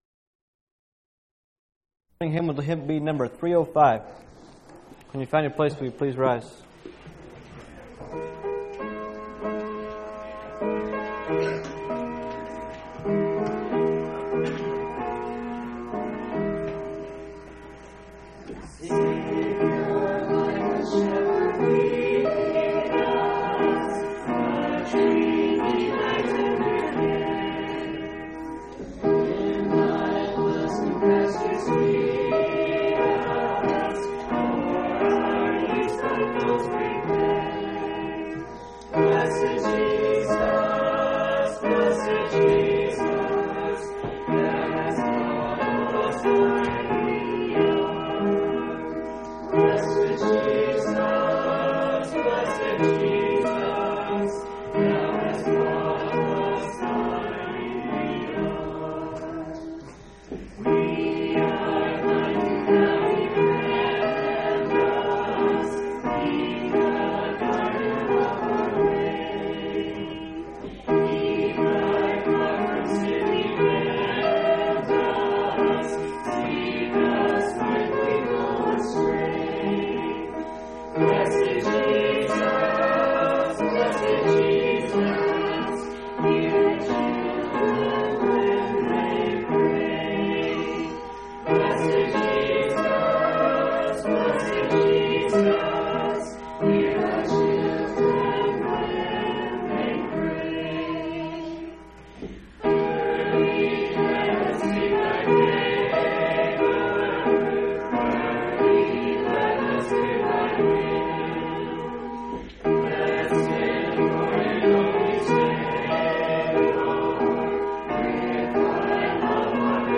2/4/1996 Location: Phoenix Local Event